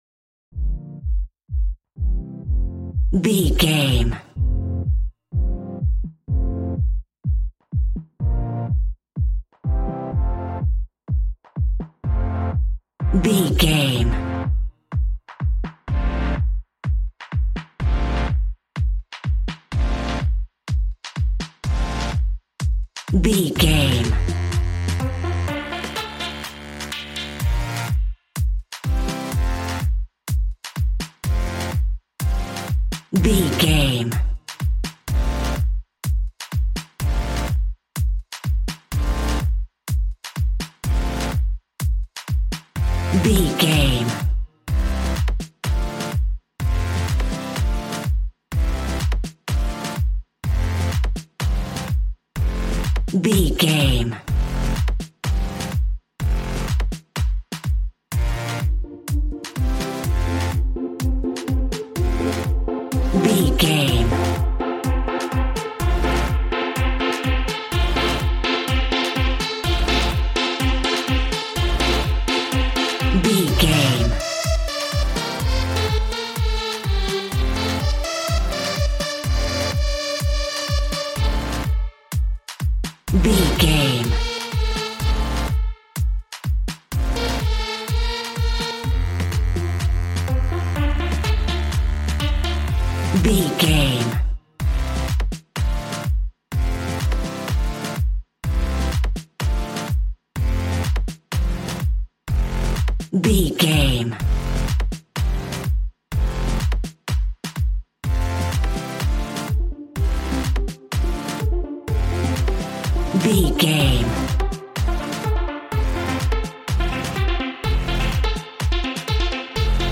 Ionian/Major
Fast
uplifting
lively
groovy
synthesiser
drums